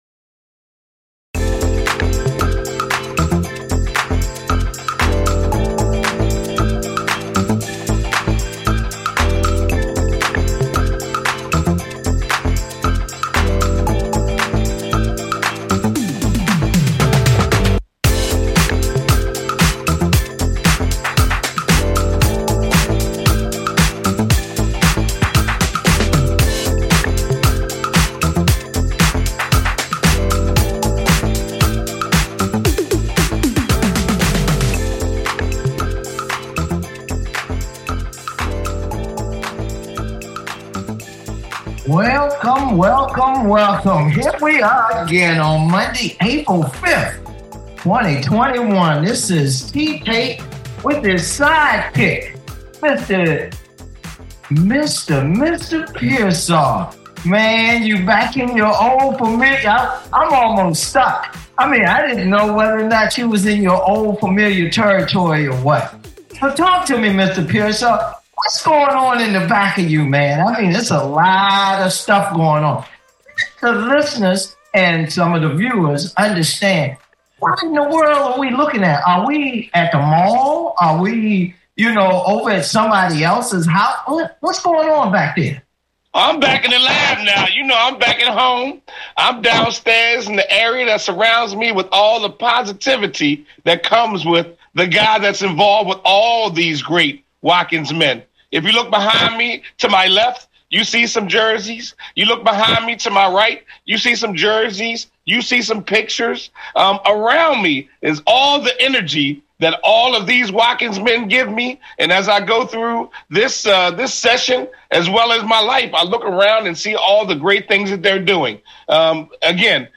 The Watkins Award Talk Show